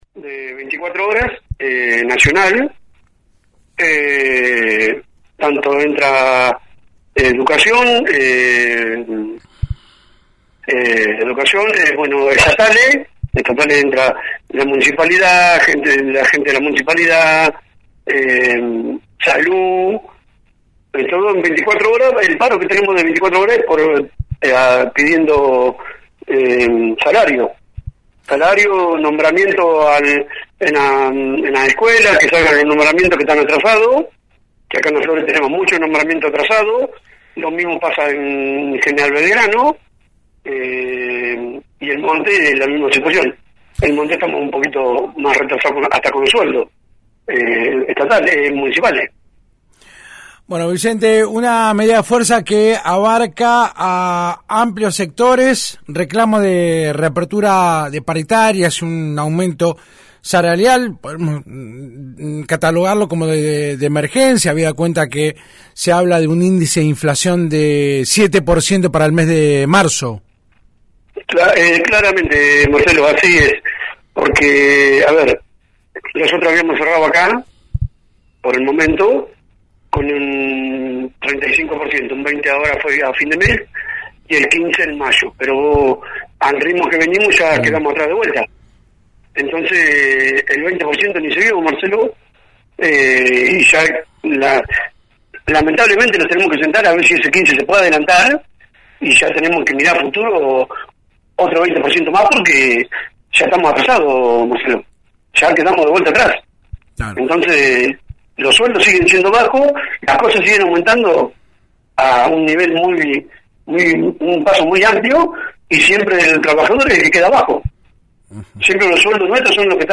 habló este martes en el programa «Súbeme la radio»